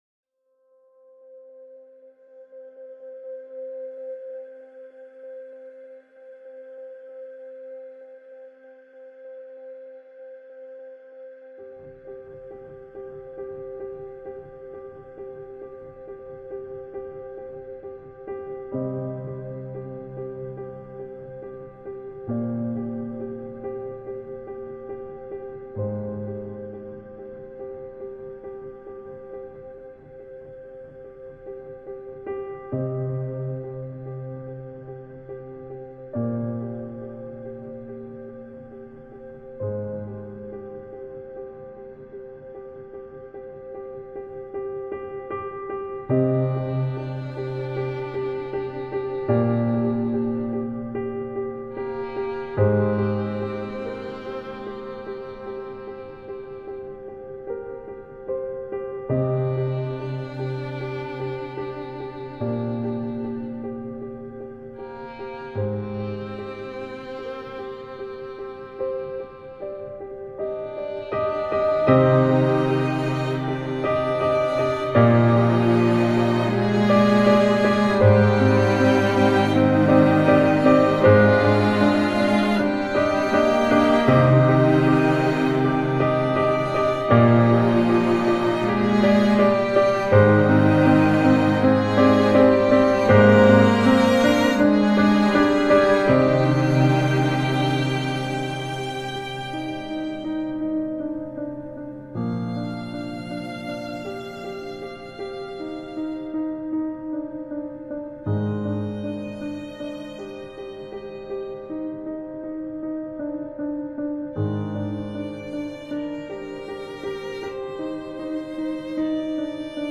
آهنگ بی کلام
موسیقی بی کلام پیانو